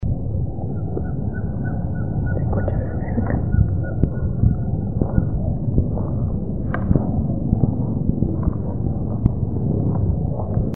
Caburé Chico (Glaucidium brasilianum)
Nombre en inglés: Ferruginous Pygmy Owl
Fase de la vida: Adulto
Localidad o área protegida: Reserva Natural Urbana La Malvina
Condición: Silvestre
Certeza: Vocalización Grabada